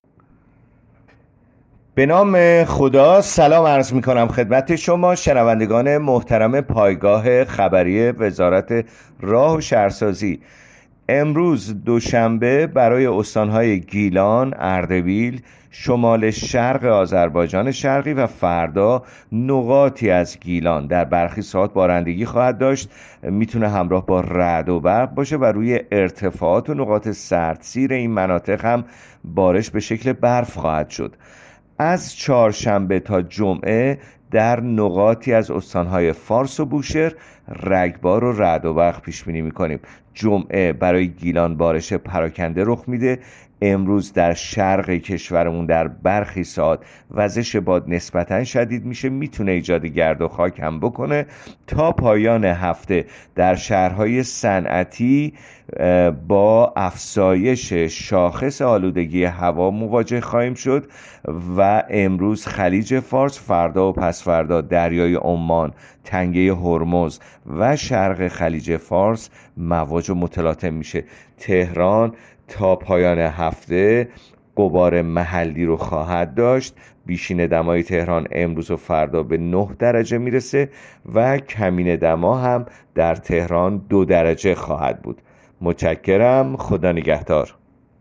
گزارش رادیو اینترنتی پایگاه‌ خبری از آخرین وضعیت آب‌وهوای ۱۲ آذر؛